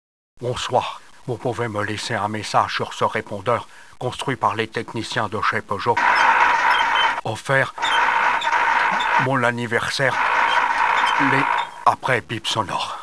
Messages d'imitations 1: